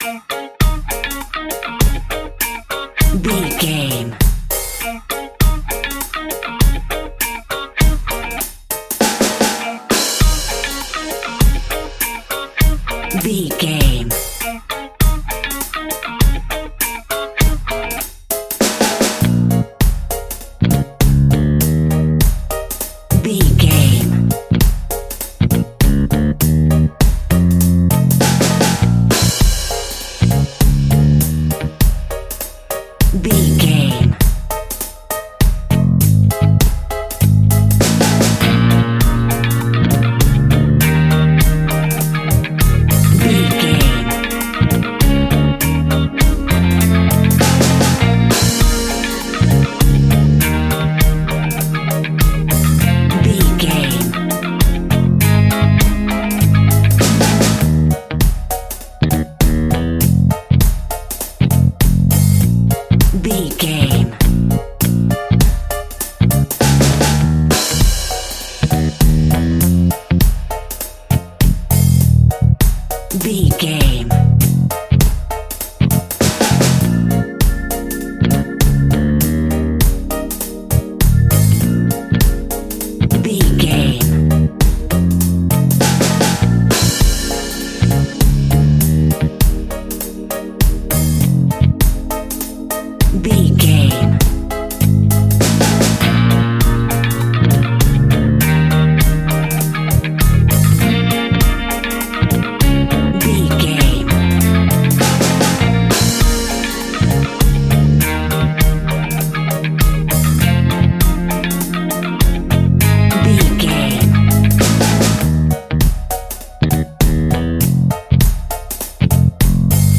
Aeolian/Minor
B♭
laid back
chilled
off beat
drums
skank guitar
hammond organ
percussion
horns